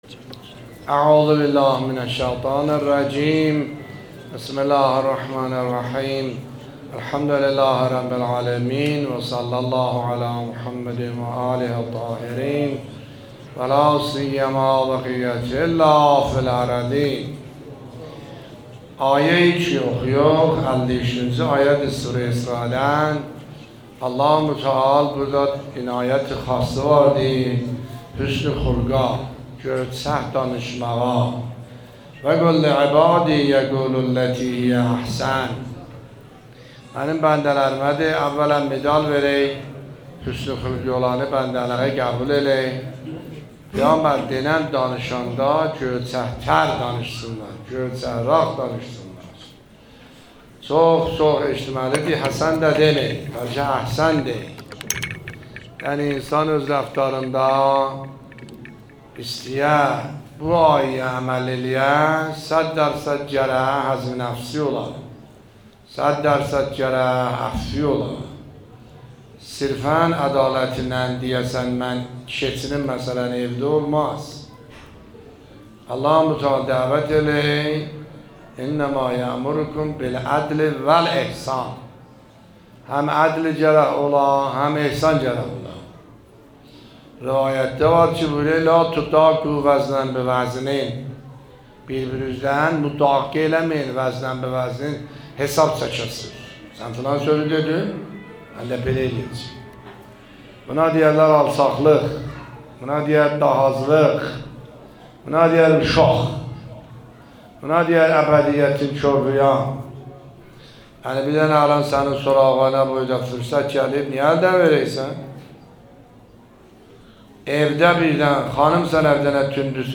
نکات برگزیده تفسیری آیه 53 سوره مبارکه اسراء در بیان دلنشین آیت الله سید حسن عاملی در مسجد مرحوم میرزا علی اکبر در سومین شب رمضان المبارک 1402 به مدت 20 دقیقه